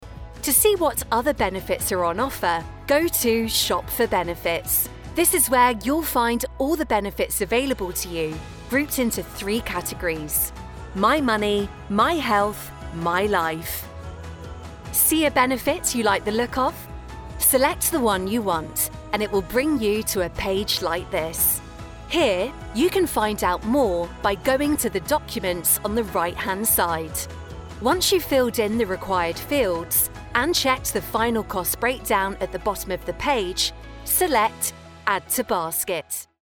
E-learning
Sennheiser MK4
Jovem adulto
Mezzo-soprano